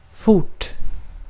Ääntäminen
US : IPA : /fɔɹt/ UK : IPA : /fɔːt/